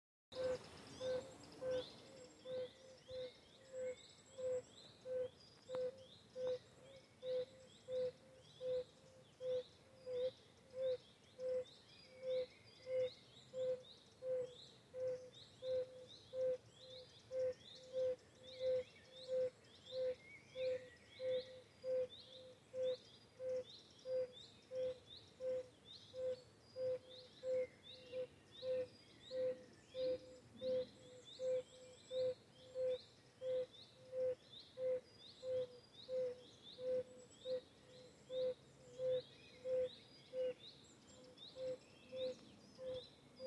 Audiodateien, nicht aus dem Schutzgebiet
Gelbbauchunke